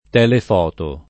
[ t H lef 0 to ]